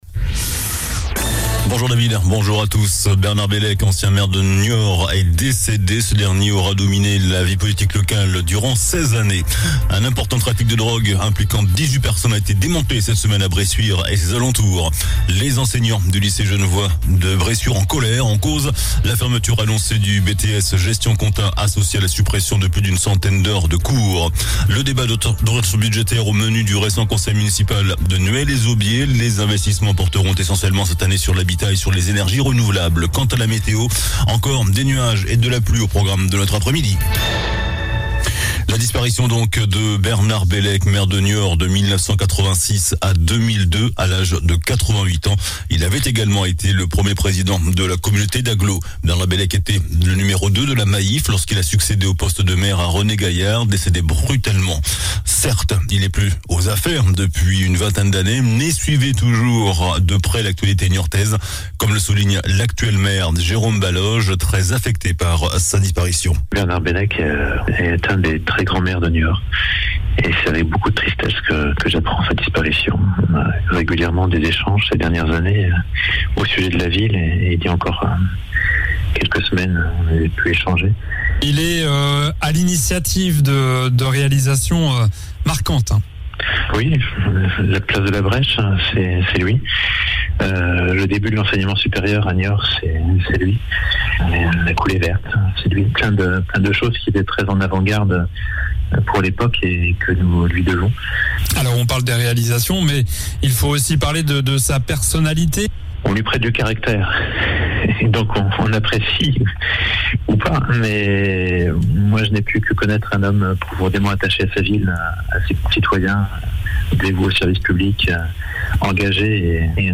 JOURNAL DU VENDREDI 24 FEVRIER ( MIDI )